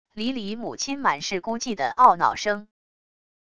离离母亲满是孤寂的懊恼声wav音频